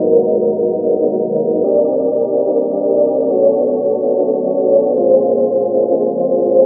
K-6 Pad 1.wav